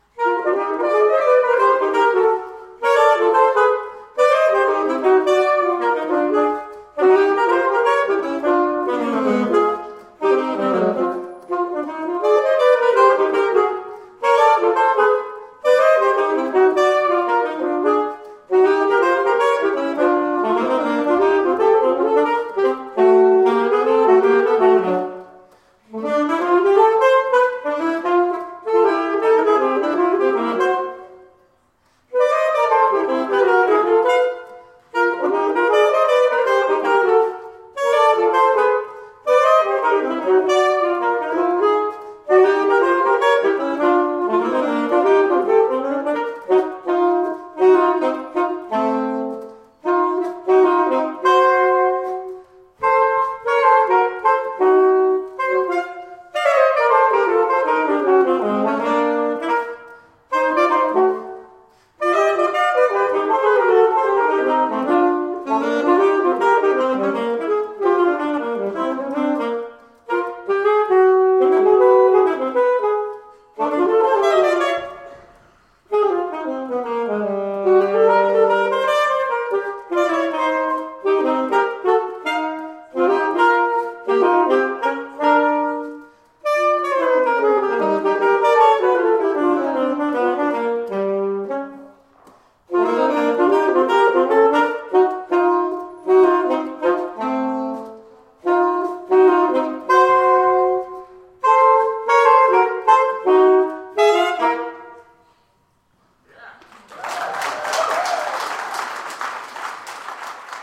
They are from the coffee house event we held as a fundraiser at Mount Calvary back in may of 2010, so they’re about 3 years old, but just now seeing the light of day.
saxophone